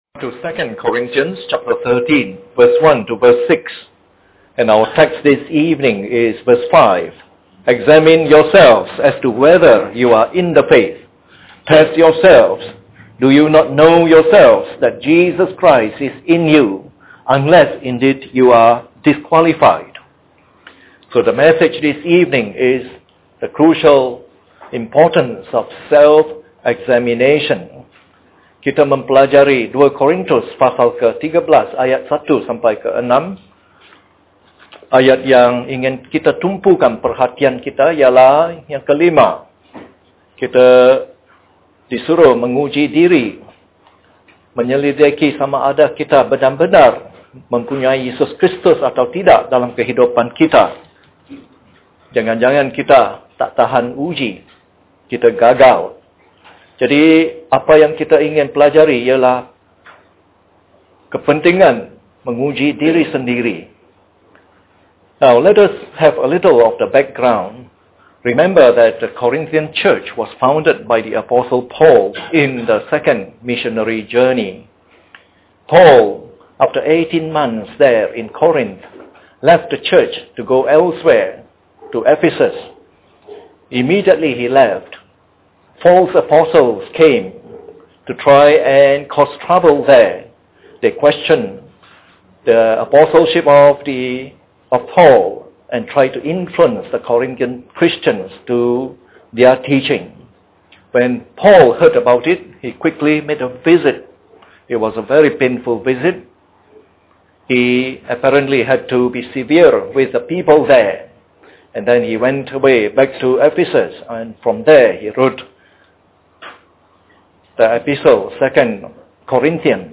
This is part of the “Selected 2 Corinthians” evangelistic series delivered in the Evening Service.